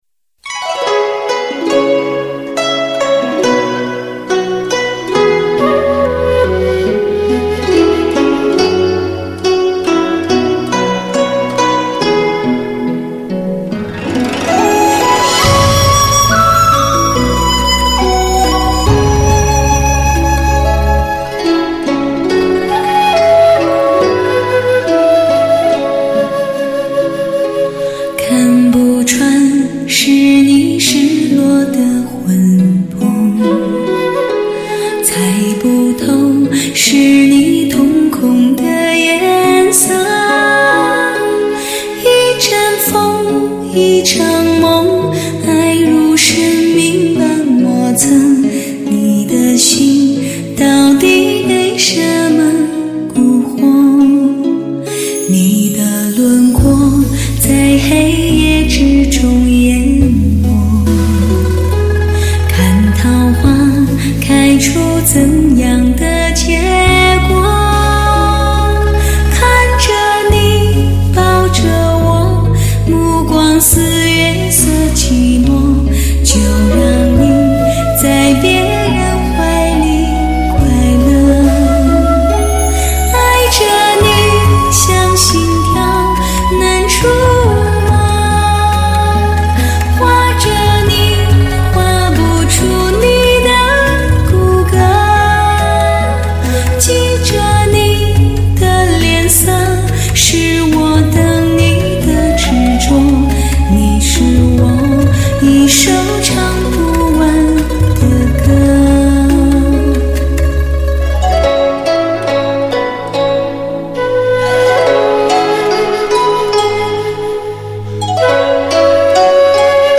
专辑格式：DTS-CD-5.1声道
录音技术：德国真空管录音